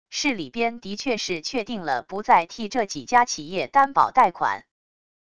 市里边的确是确定了不再替这几家企业担保贷款wav音频生成系统WAV Audio Player